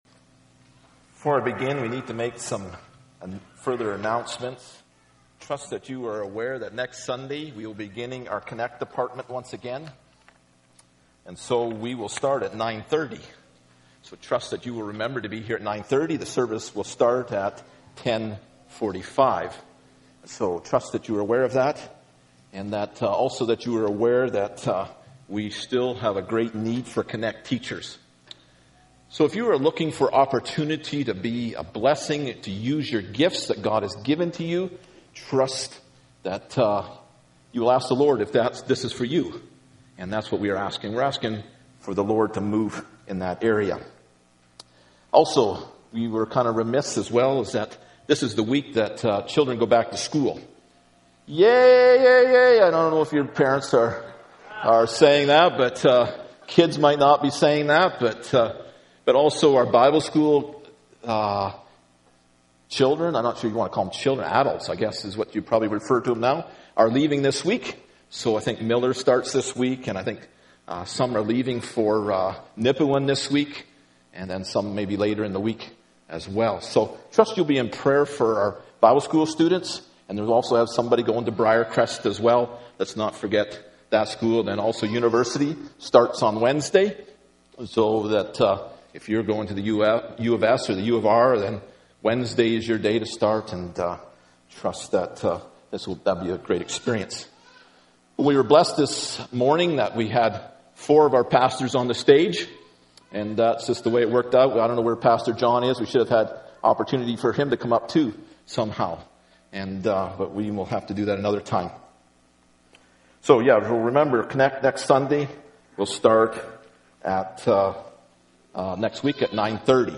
Ezekiel 8 Service Type: Sunday Morning Bible Text